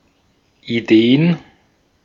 Ääntäminen
Ääntäminen Tuntematon aksentti: IPA: /ʔiˈdeːən/ Haettu sana löytyi näillä lähdekielillä: saksa Käännöksiä ei löytynyt valitulle kohdekielelle.